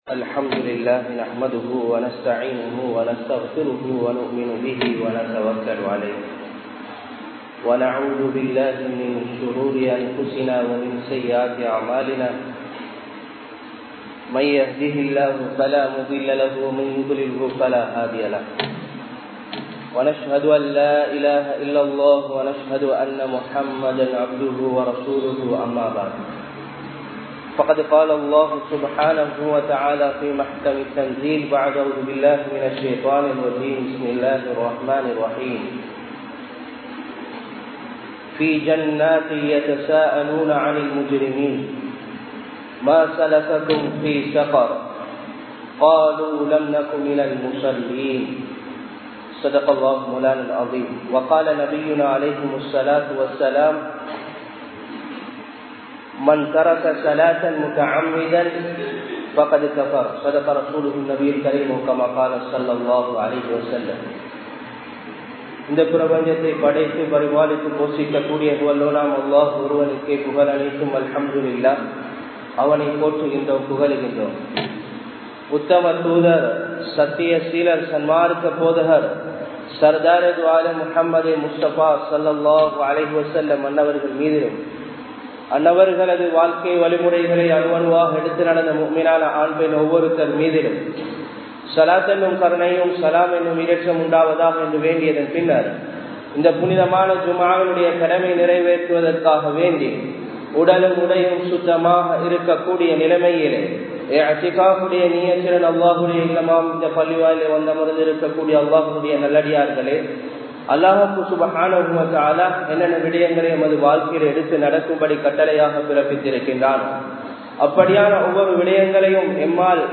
Tholuhaien Avasiyam (தொழுகையின் அவசியம்) | Audio Bayans | All Ceylon Muslim Youth Community | Addalaichenai